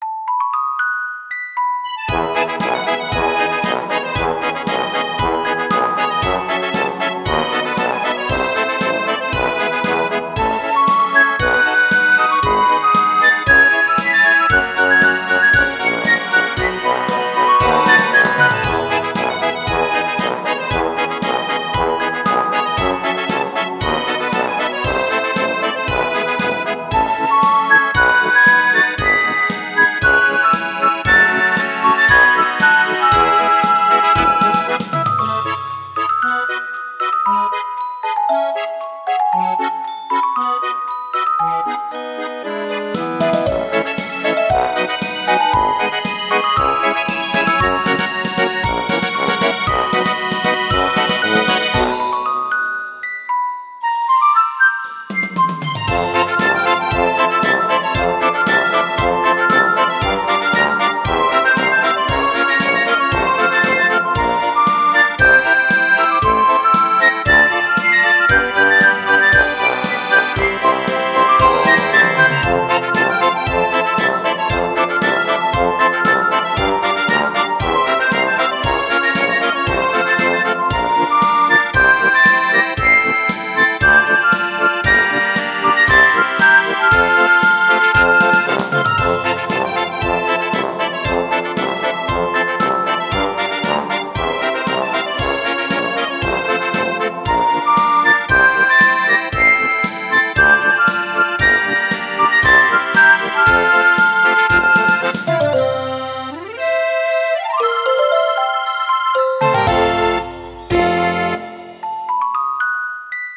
ogg(L) メルヘン 幻想的 妖精楽隊